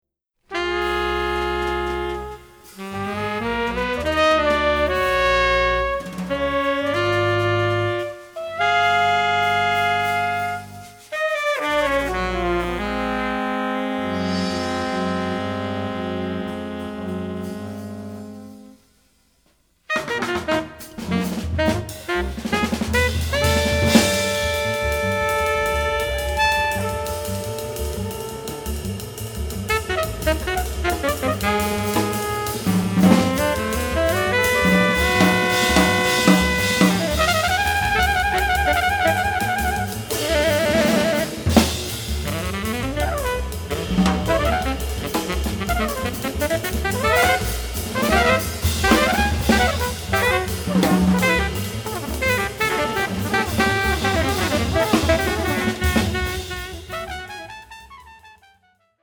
Genre: Jazz.
tenor saxophone
trumpet
an odd, monaural sound